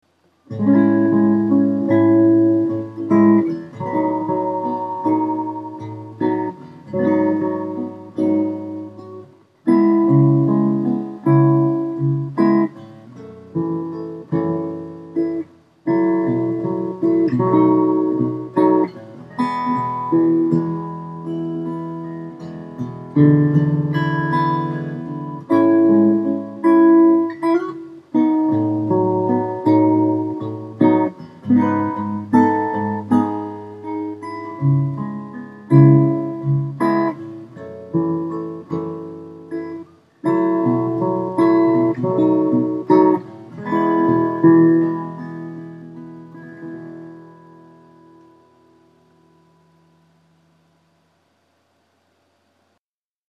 この音源は加工等一切しておりませんので、多少お聞き苦しい点もあるかと思いますが、何度か繰り返し聞いていただきたいと思います。
2. コンタクトマイクのみのサウンド
箱鳴りのエアー感（空気感）が特に強調されるので、アコースティック感を出したい場合にはおすすめです。